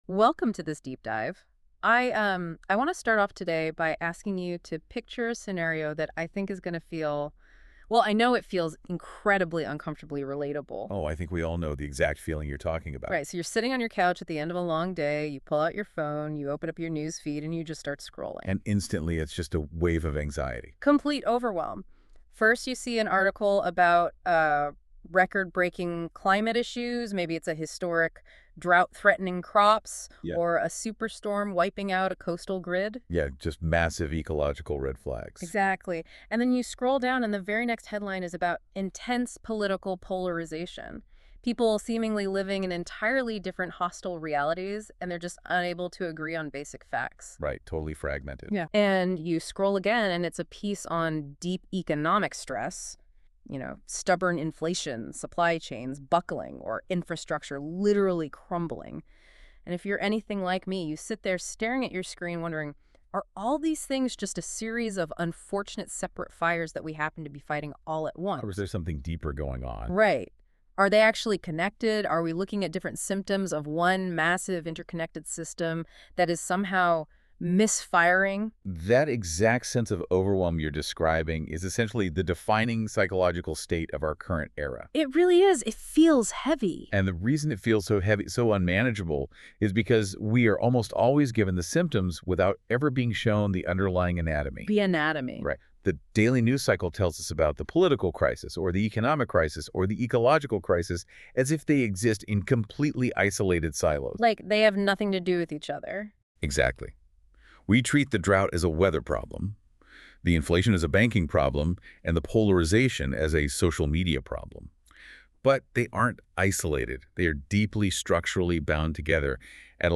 Reflexive Civilizational Governance: Life-Ground Viability and the Architecture of Human Survival | ChatGPT5.3 & Gemini (Figures) & NotebookLM - TOWARDS LIFE-KNOWLEDGE
Deep Diver Audio Overview